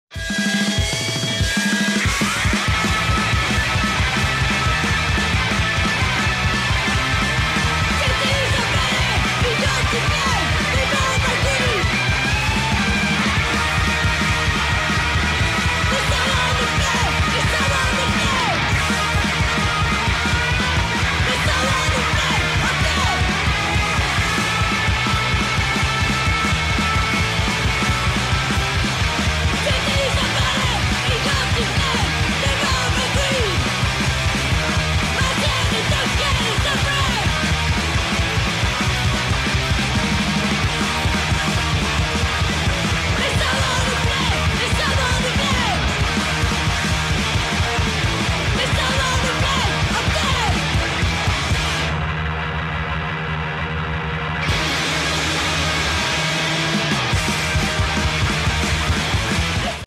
hardcore punk / D-beat crust band